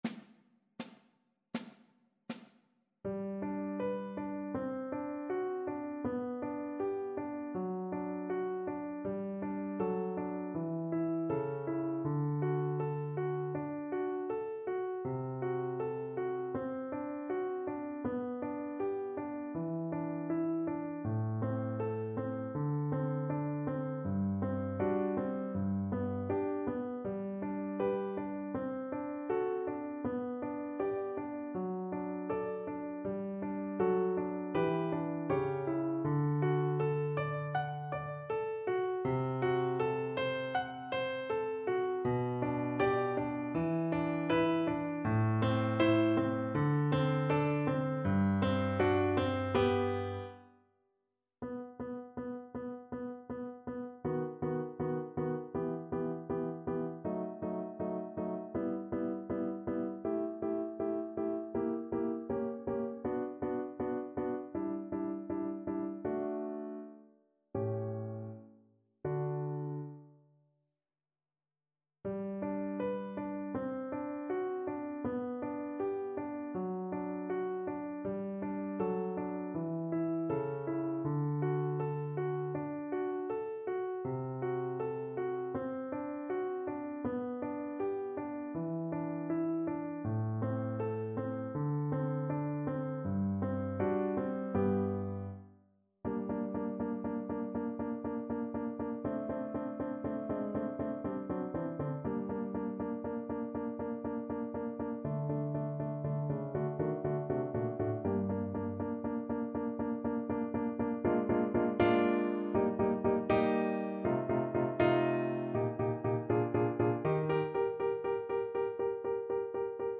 Beethoven: Sonata Patetyczna (na wiolonczelę i fortepian)
Symulacja akompaniamentu